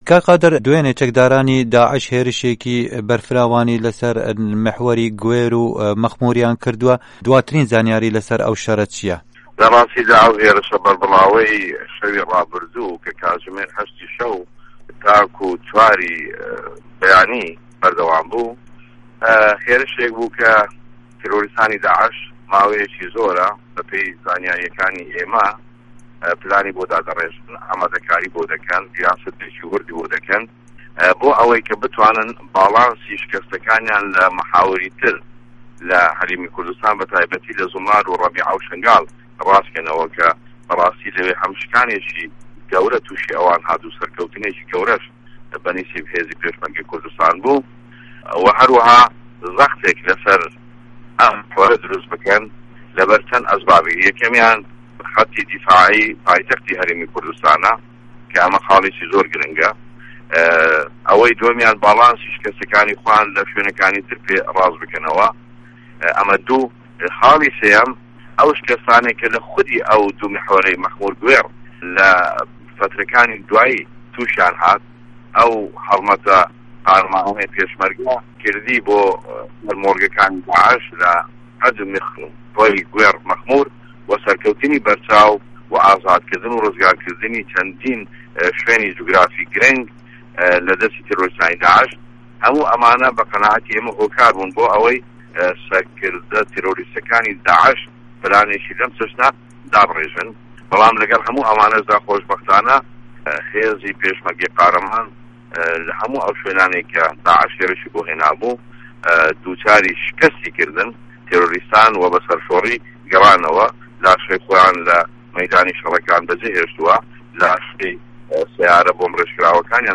Endamê berê yê parlemana Herêma Kurdistanê û Pêşmergeyê xwebexş Qadir Hesen di hevpeyvînekê di ligel Dengê Amerîka dibêje, êrîşa teroristên Daîşê bi serperştîya Ebû Bekir el Bexdadî bû û bizavek bû da şikestinên xwe li berokên di yên şerî veşêrin, lê careke dî êrîşa wan hate şikandin.
hevpeyvin digel Qadir Hassan